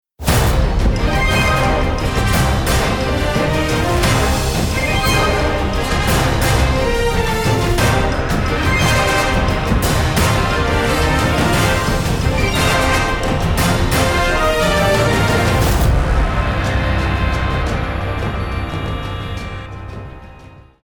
Game Music
orchestral
piano violin